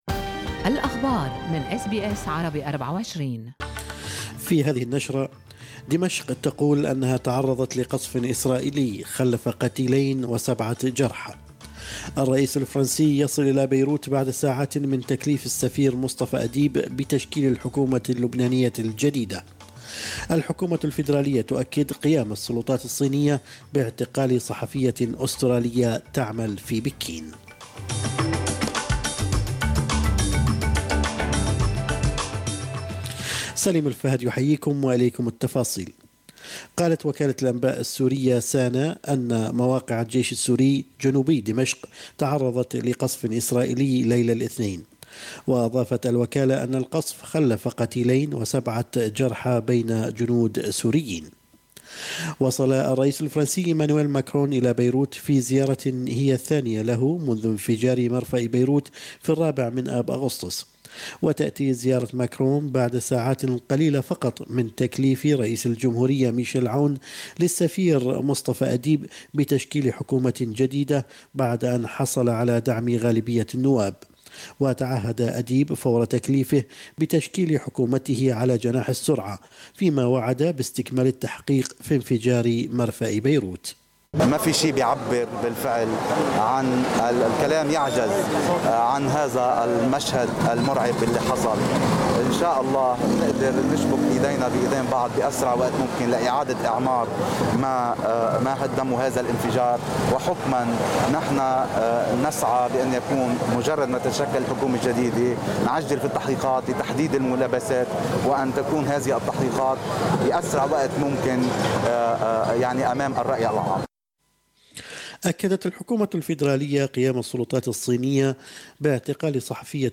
نشرة أخبار الصباح 1/9/2020